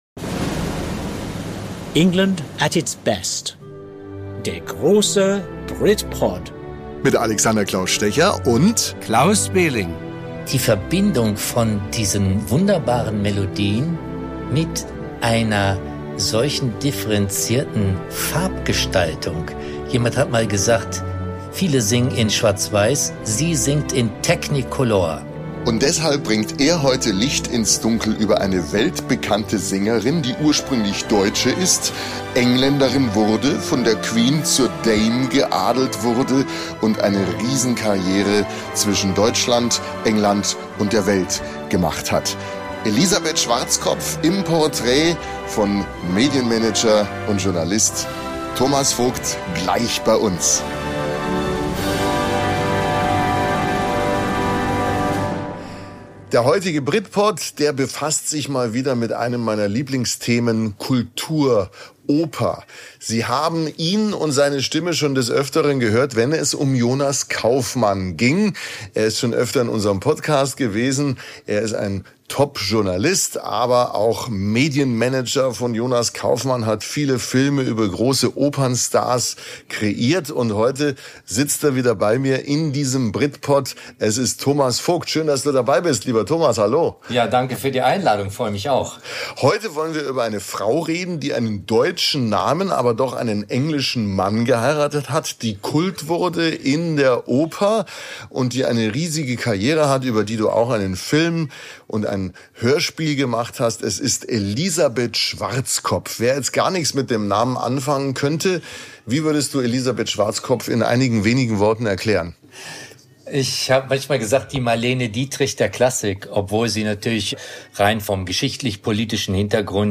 Die Folge enthält exklusive Anekdoten, persönliche Einschätzungen und seltene Tonaufnahmen – ein Streifzug durch ein Jahrhundert der Musikgeschichte und das Porträt einer Frau, die zur musikalischen Stilikone wurde.